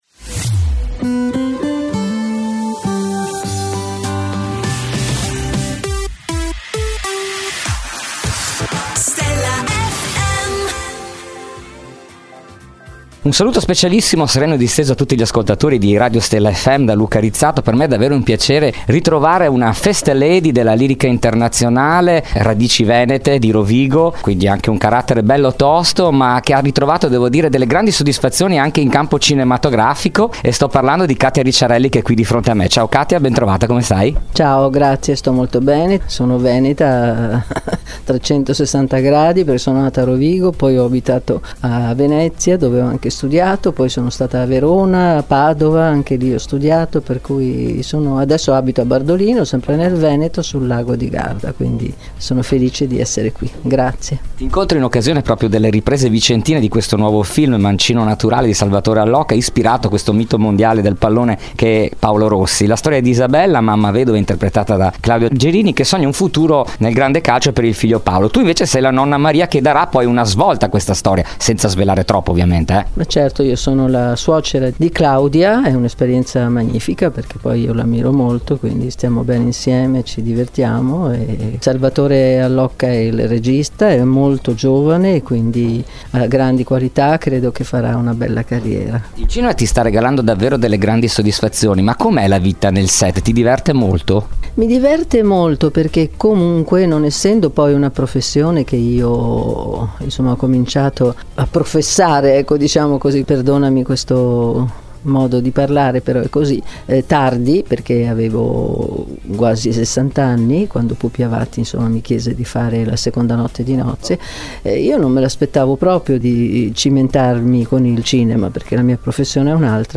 Intervista I Katia Ricciarelli | Stella FM
Intervista esclusiva dell’inviato per Stella FM a Katia Ricciarelli.